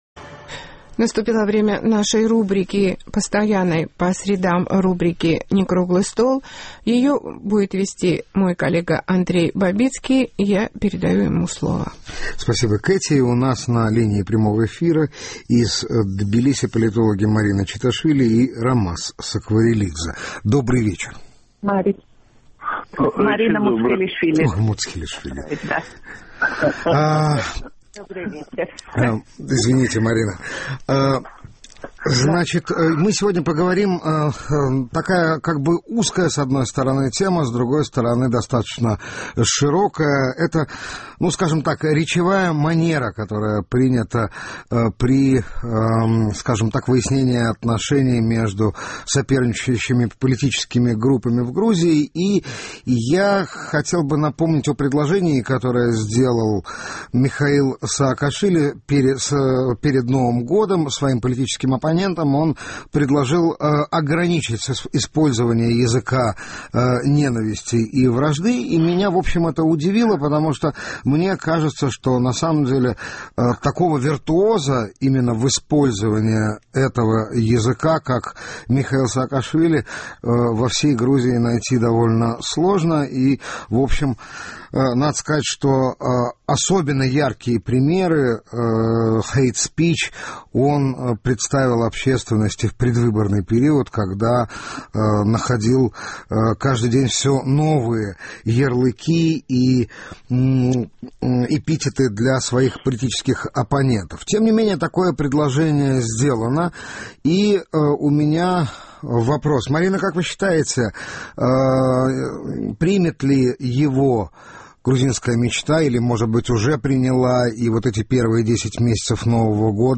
в прямом эфире из Тбилиси